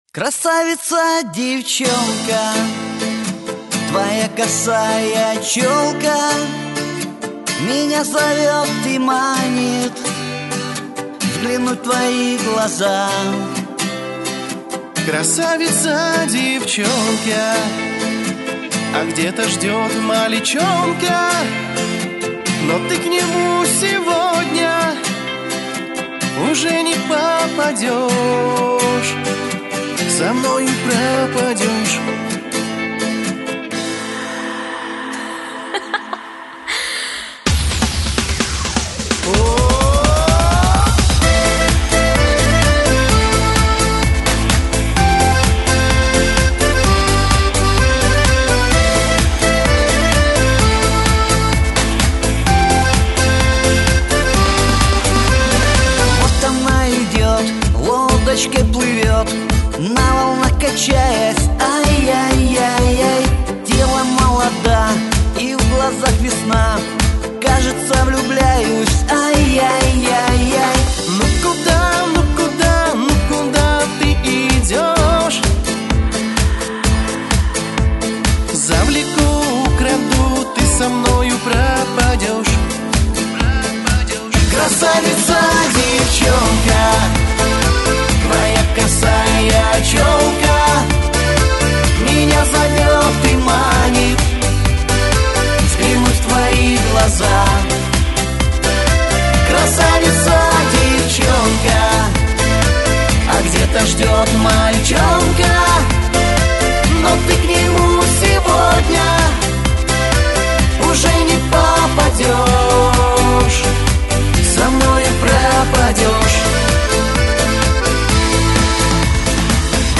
Всі мінусовки жанру Shanson
Плюсовий запис